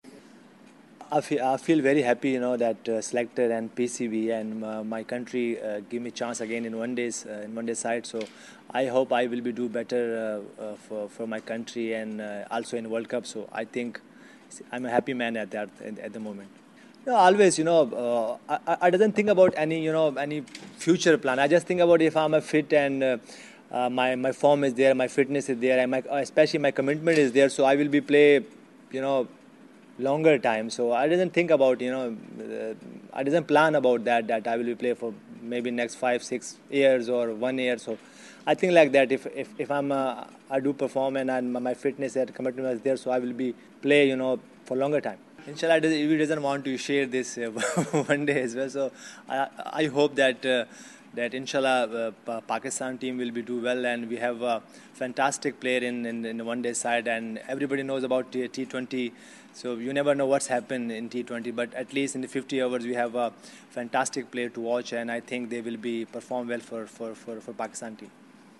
Younus Khan, the Pakistan batsman, media conference, 7 December (English)